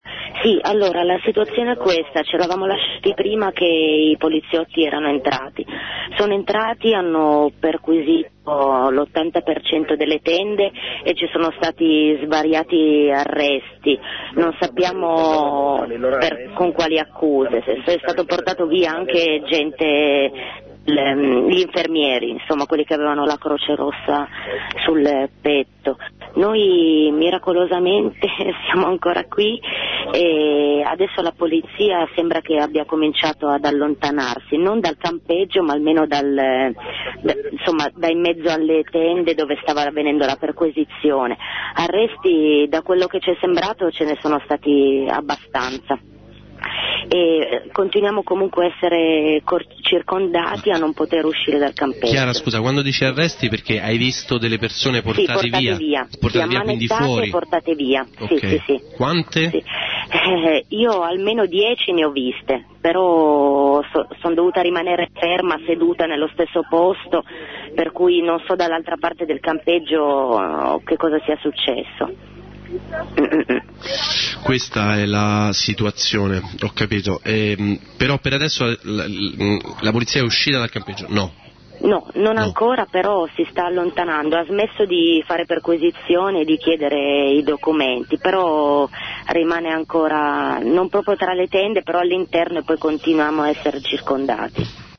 Campeggio Losanna
dalcampeggio3.mp3